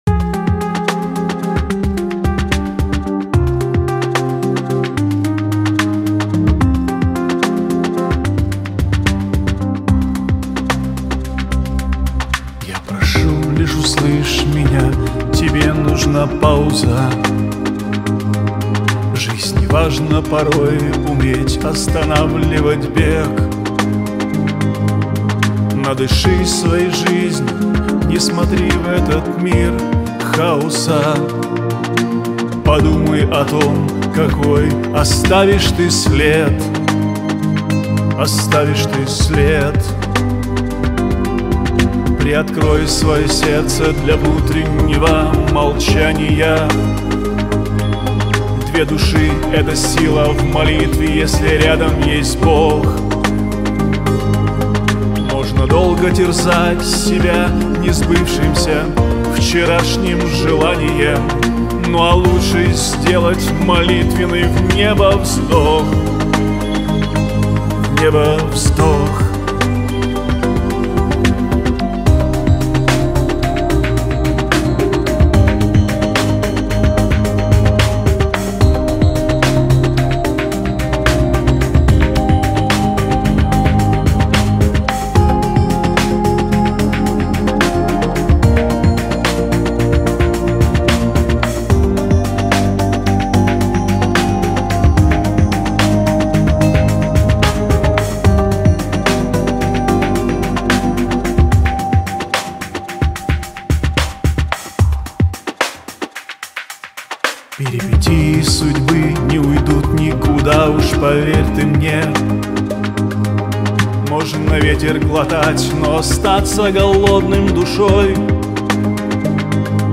121 просмотр 57 прослушиваний 4 скачивания BPM: 80